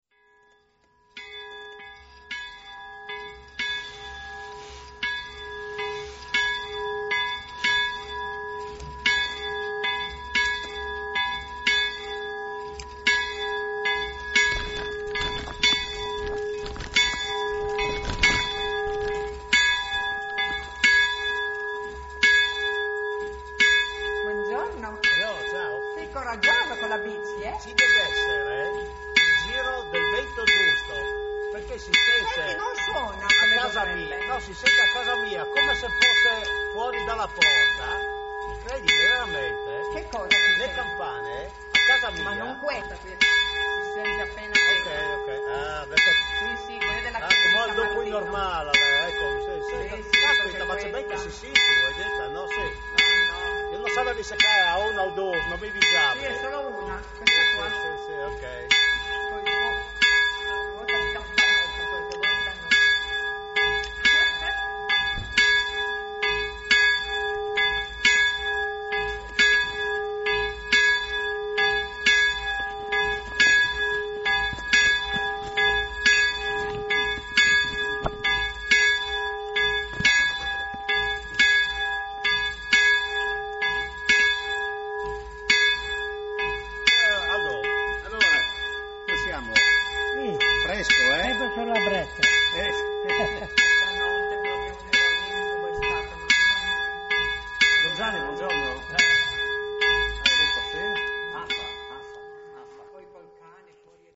Leproso di Premariacco (UD), 10 Agosto 2024
Santa Messa nella chiesetta di San Lorenzo
CANTO E PREGHIERE DI APERTURA